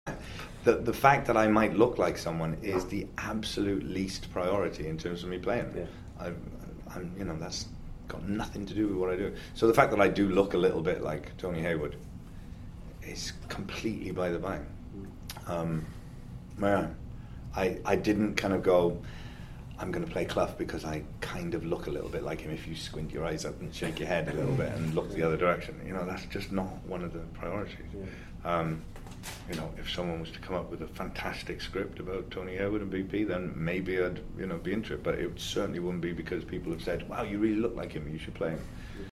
I’ve posted three short audio files below from the round table interview with Michael last month at a London hotel.